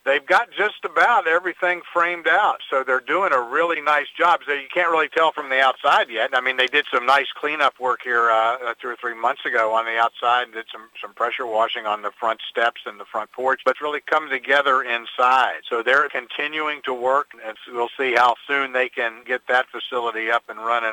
Built in 1902 and once a federal building, work is progressing on event space, a restaurant and short- and long-term apartments.  Mayor Ray Morriss says the work inside is coming along…